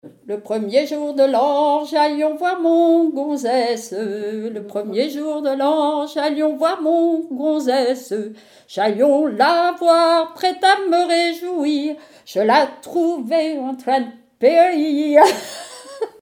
Genre strophique
témoignages et bribes de chansons
Pièce musicale inédite